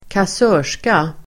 kassörska substantiv, [female] cashier Uttal: [²kas'ö:r_ska] Böjningar: kassörskan, kassörskor Synonymer: kassör Definition: kvinnlig kassör cashier substantiv, kassör , kassörska , person som har hand om en kassa